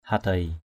/ha-d̪eɪ/
hadei.mp3